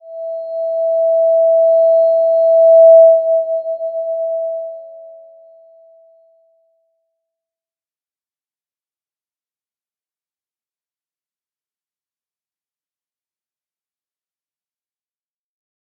Slow-Distant-Chime-E5-p.wav